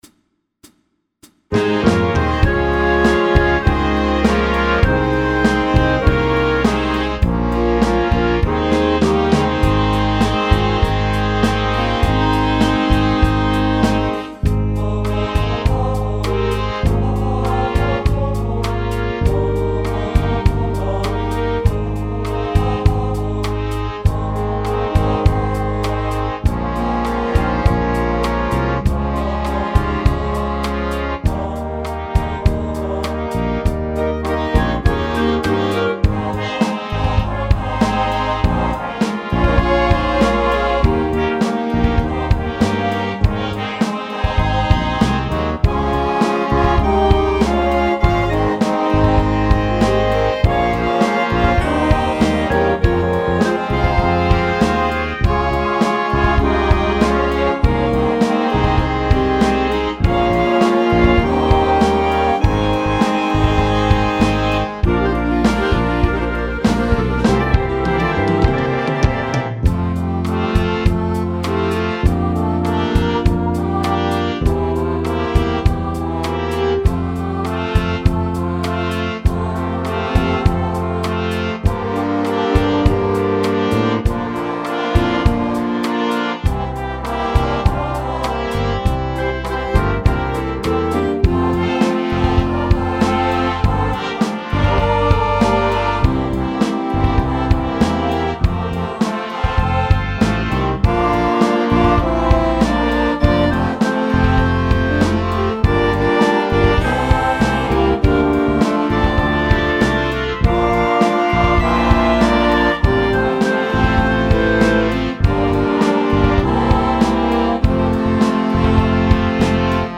Dychová hudba Značiek
Slow , Spev Zdieľajte na